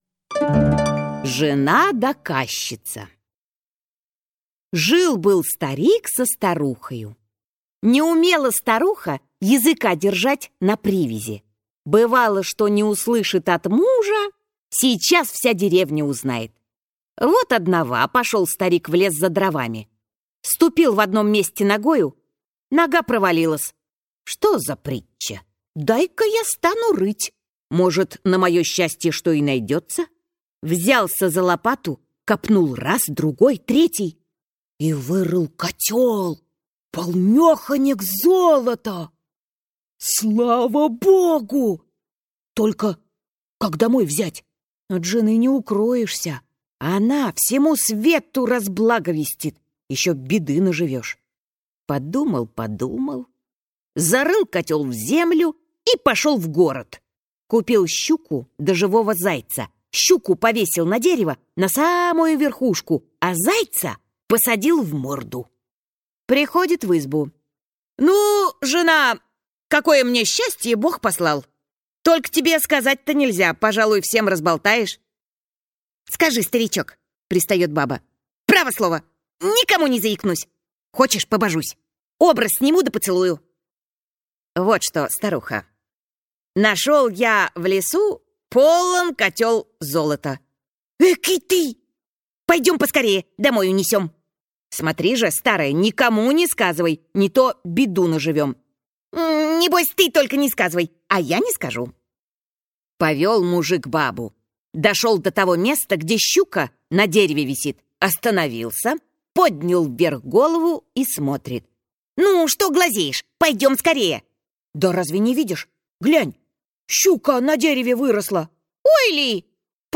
Аудиокнига Затейки. Сказки. Загадки. Поговорки. Скороговорки | Библиотека аудиокниг